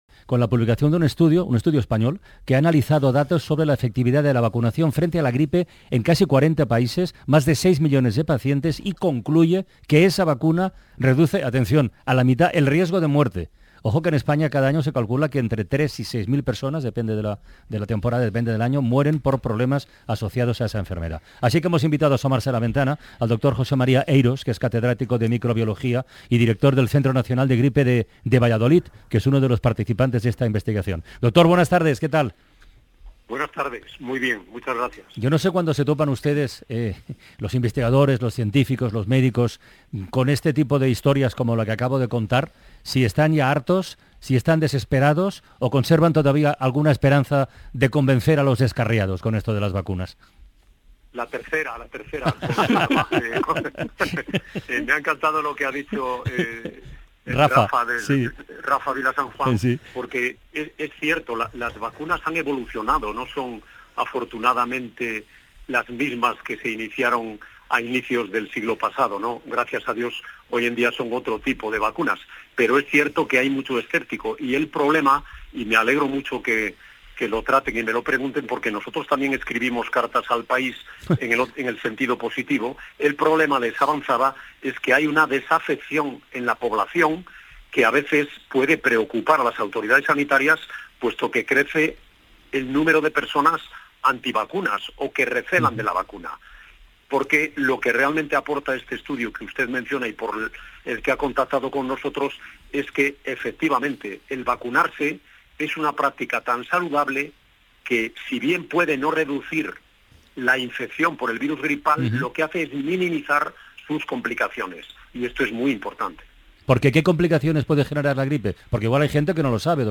Su conclusión es contundente: la vacuna contra la gripe reduce a la mitad el riesgo de muerte. Sobre este asunto fue entrevistado en el programa "La Ventana", de la Cadena SER: